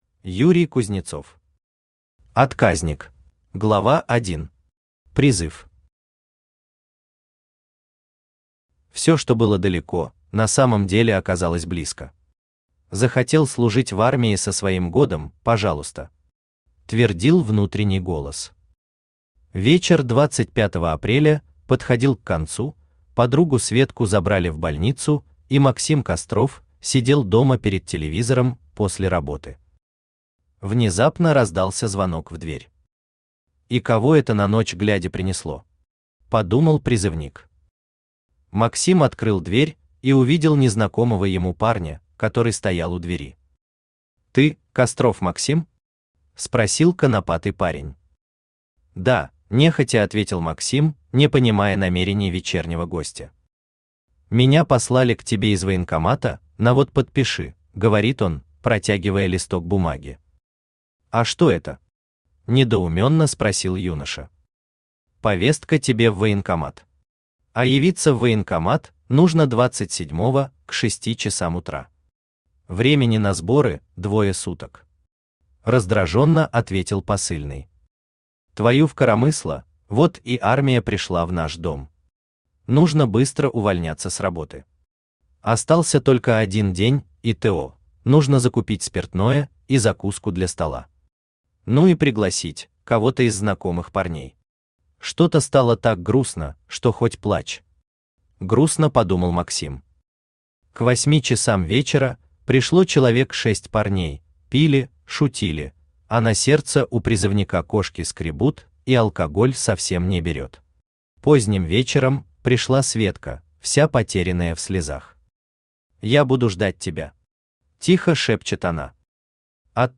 Аудиокнига Отказник | Библиотека аудиокниг
Aудиокнига Отказник Автор Юрий Юрьевич Кузнецов Читает аудиокнигу Авточтец ЛитРес. Прослушать и бесплатно скачать фрагмент аудиокниги